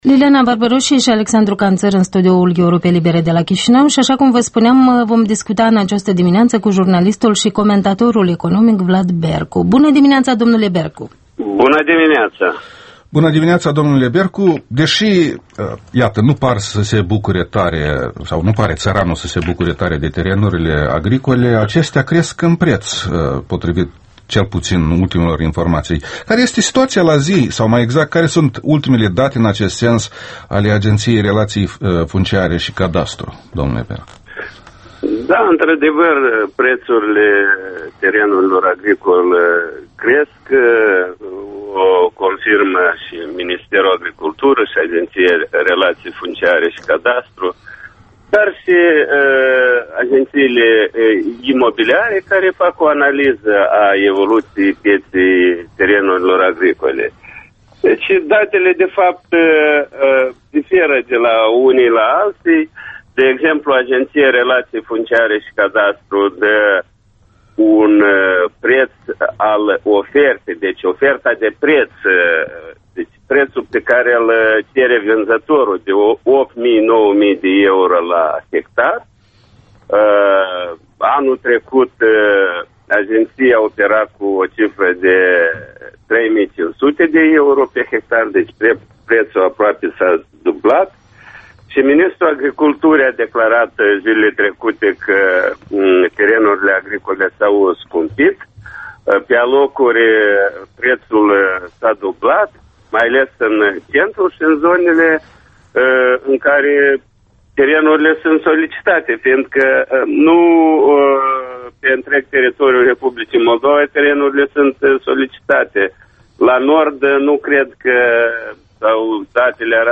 Interviul matinal